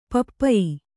♪ pappayi